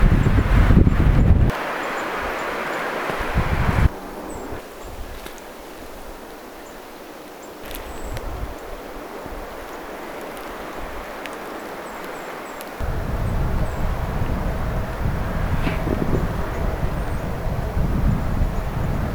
vielä vähän niiden puukiipijälintujen
ääntelyä, kooste
ne tiaismaisetkin äänet.
viela_vahan_puukiipijalintujen_aantelya_kooste.mp3